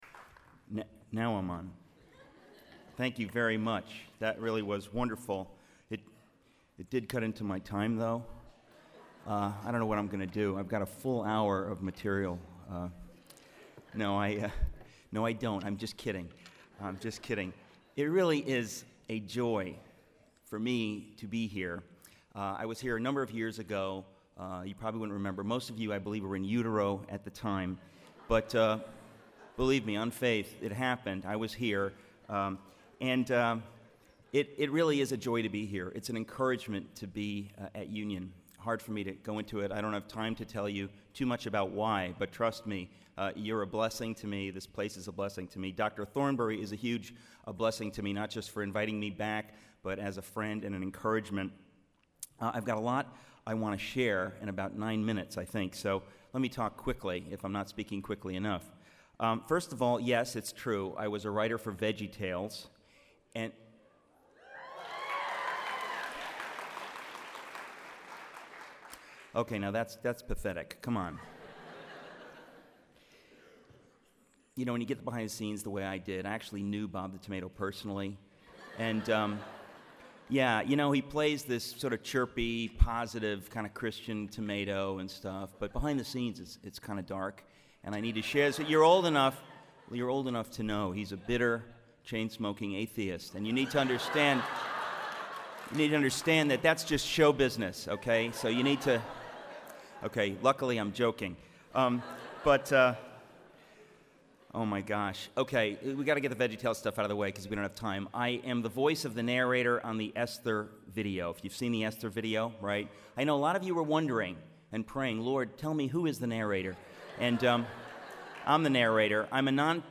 Chapel: Eric Metaxas
Address: How Good Overcomes Evil: Lessons from the Life and Death of Dietrich Bonhoeffer- Rev. 2:1-5